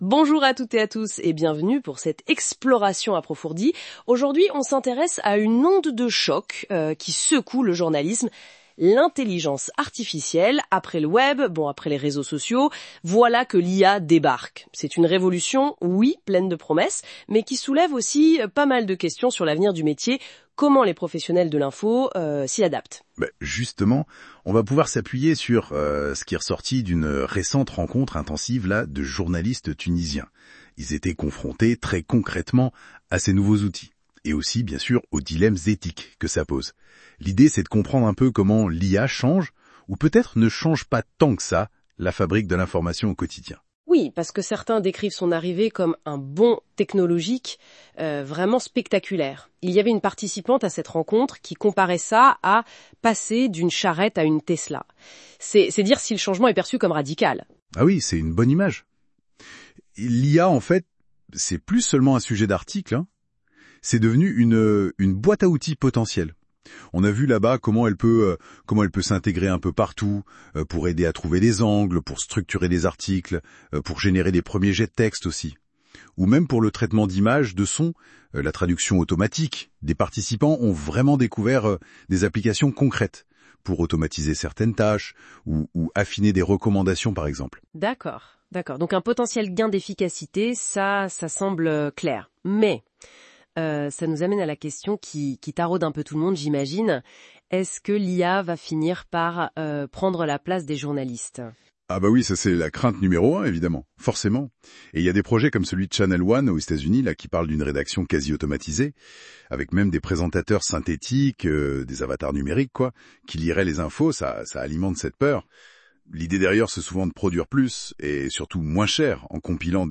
En effet, nous avons combiné plusieurs outils pour générer du texte, des images et même du son